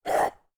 femalezombie_spotted_01.ogg